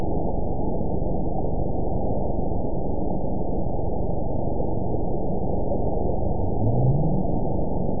event 922864 date 04/29/25 time 02:55:29 GMT (7 months ago) score 9.48 location TSS-AB02 detected by nrw target species NRW annotations +NRW Spectrogram: Frequency (kHz) vs. Time (s) audio not available .wav